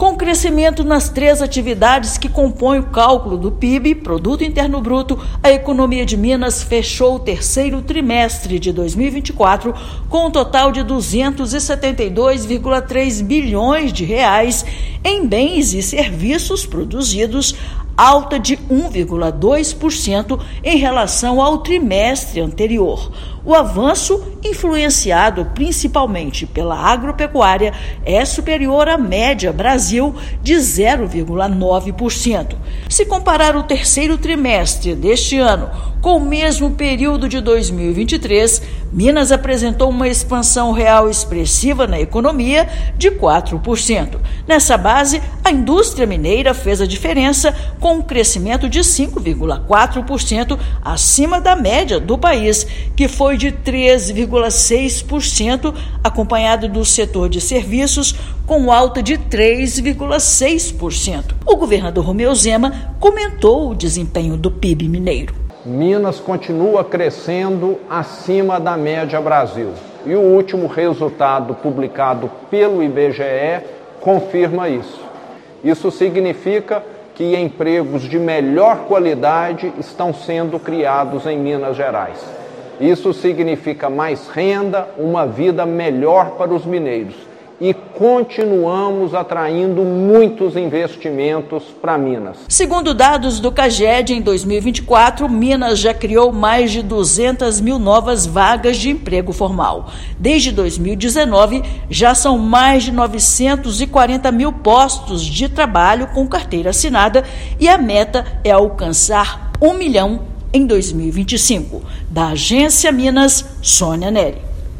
De julho a setembro, economia de Minas cresceu 1,2% na comparação com os três meses anteriores enquanto a média nacional foi de alta de 0,9%. Ouça matéria de rádio.